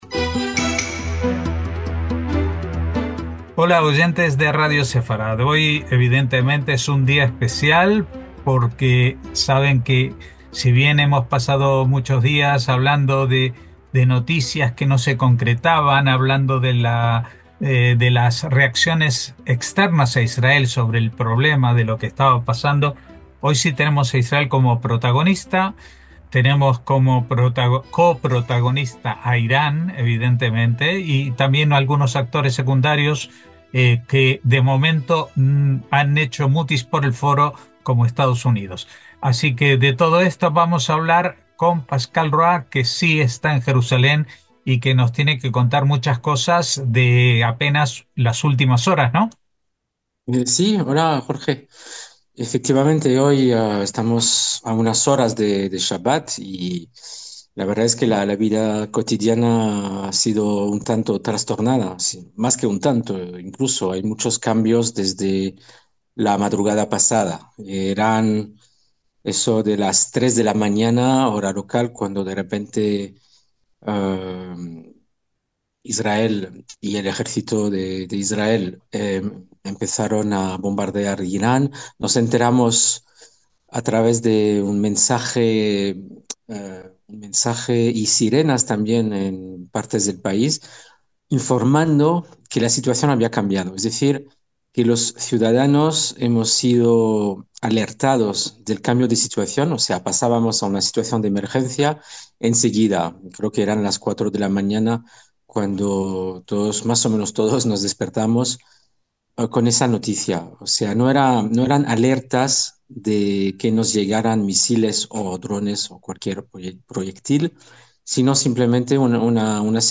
NOTICIAS CON COMENTARIO A DOS - Es posible que en los próximos días lean otra versión traducida del operativo de Israel contra Irán iniciado en la madrugada del 13 de junio, que en hebreo se llama "Am keleviá" (en la imagen).